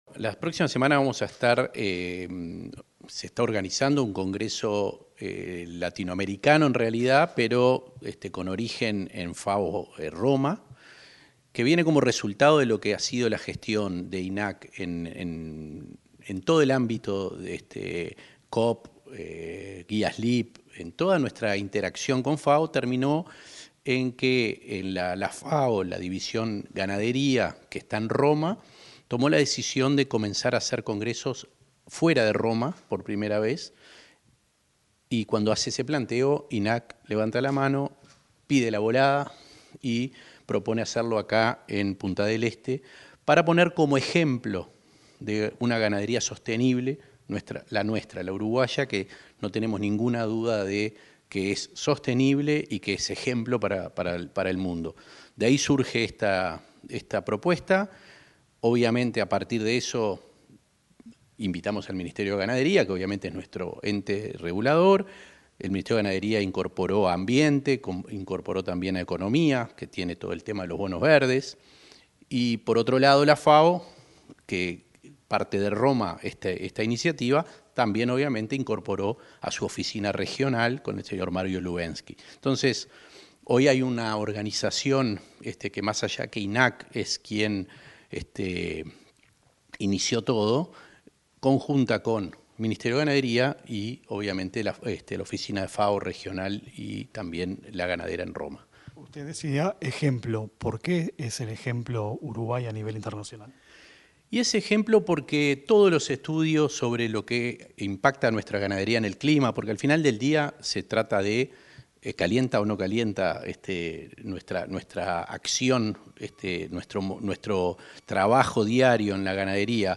Entrevista al presidente del INAC, Conrado Ferber